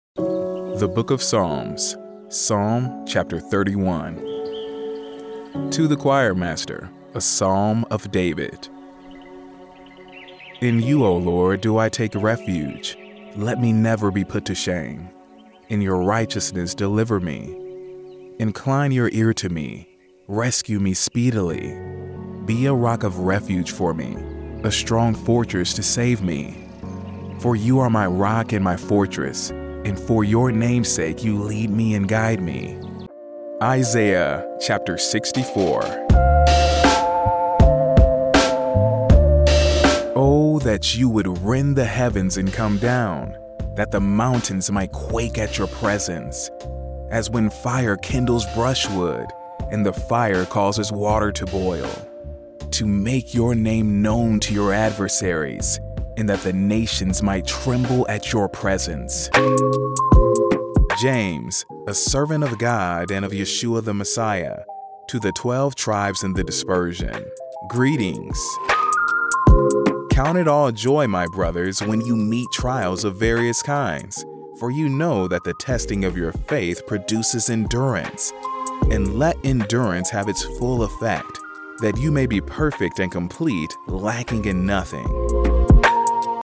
Meditative - Warm, Engaging, Authentic
From his home studio he narrates voiceovers with an articulate, genuine, conversational, and authentic feel.